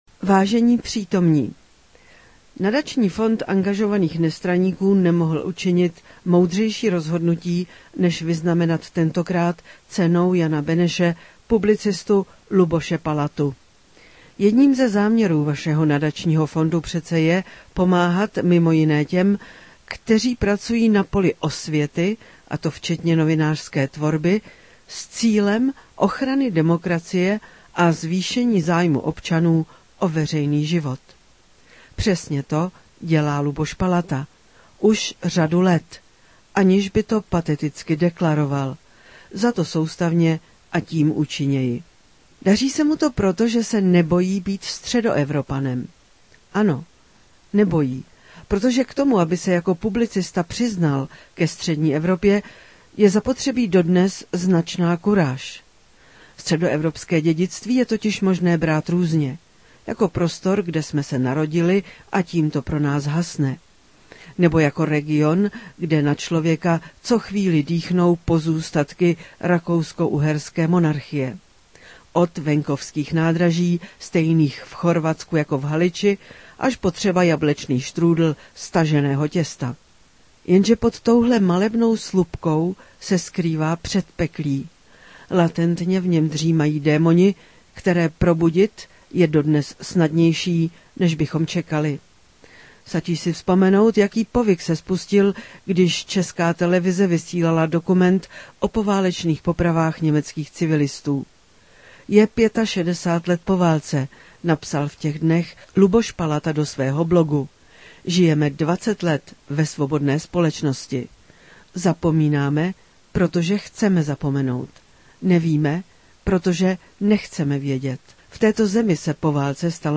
Laudatio
laudatio.mp3